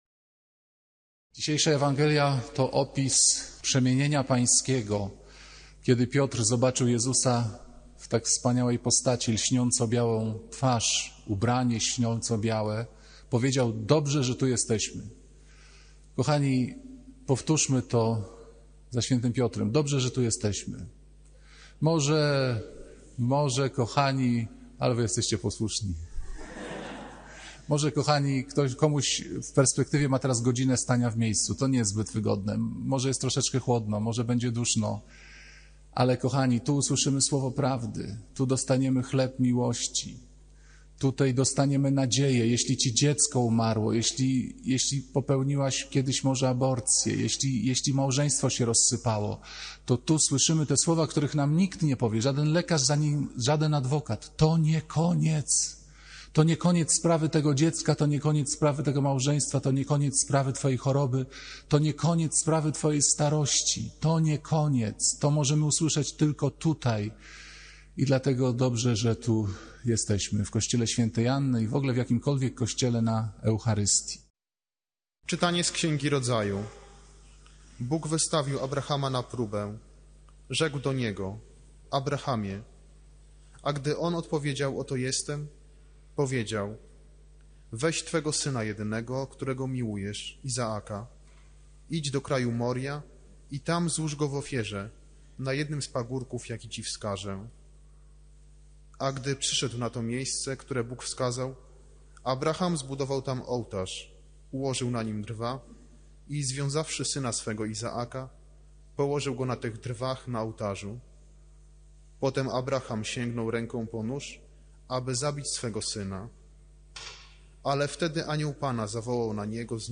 Kazania księdza Pawlukiewicza o zaufaniu i posłuszeństwie.